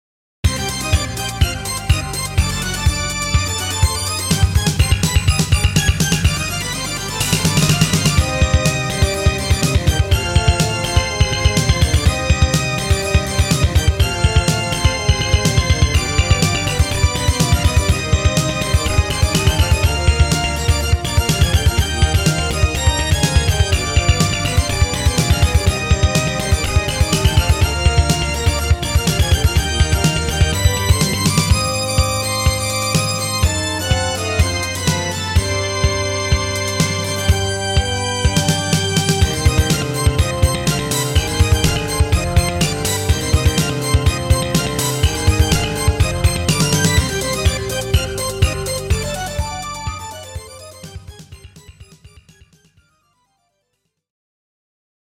耳コピーを試みたけど、ボディ部分が完成するまでもなく、あえなく撃沈。
※音源はＳＣ８８Ｐｒｏ